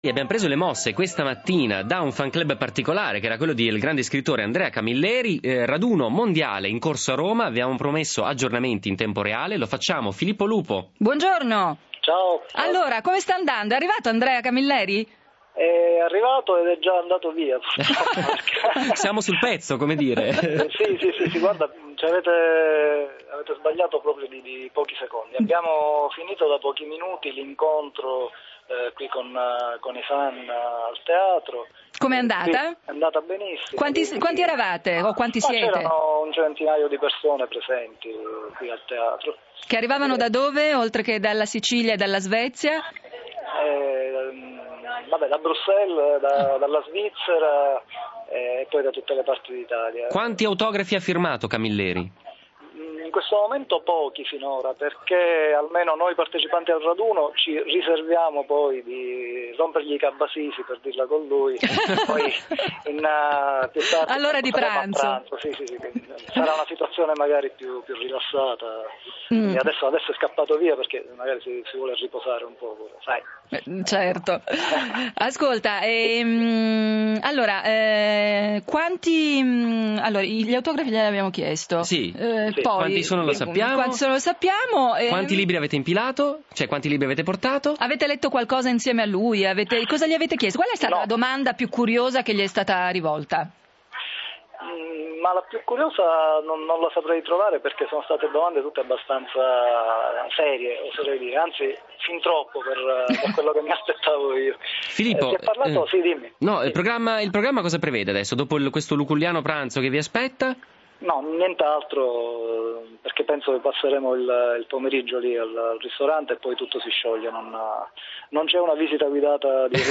La trasmissione, dedicata ai Fans Club, si è collegata con il Raduno del Camilleri Fans Club.